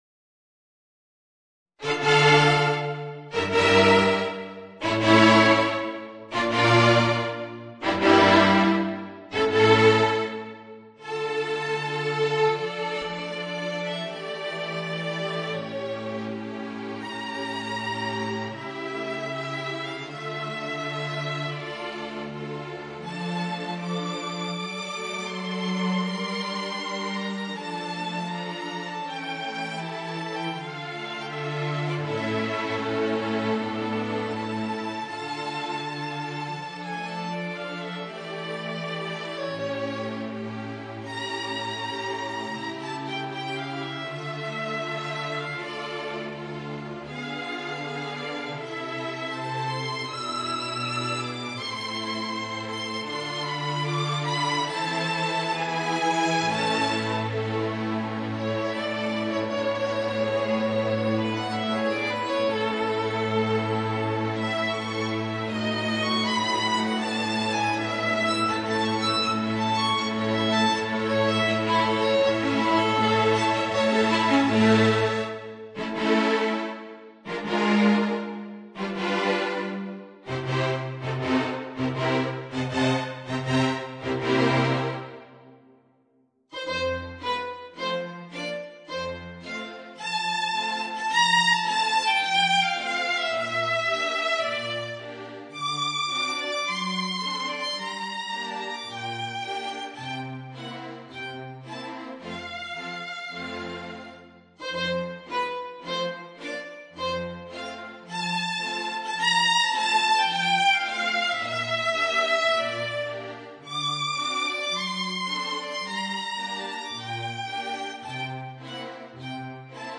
Voicing: Flute and String Quartet